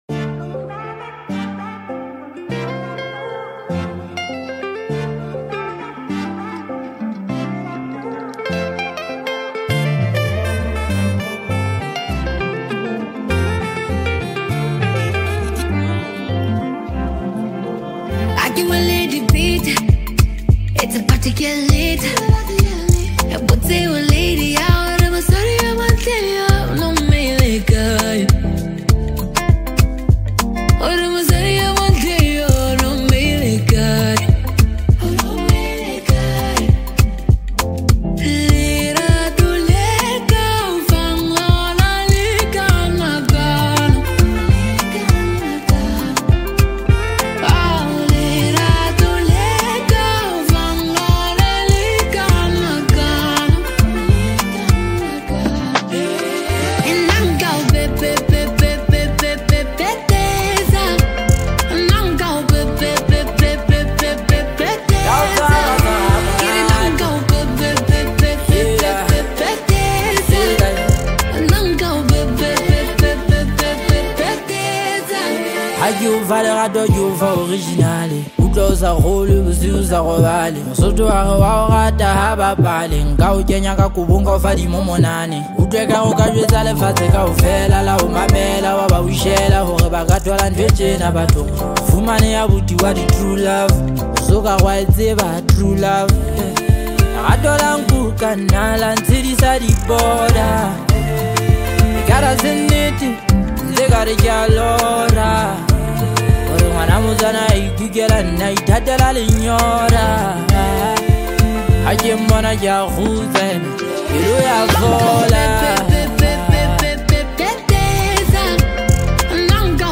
Home » Amapiano » Gqom » Lekompo